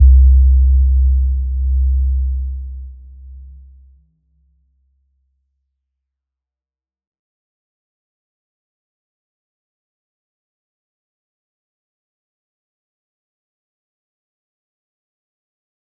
Little-Pluck-C2-p.wav